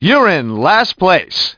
Speech
1 channel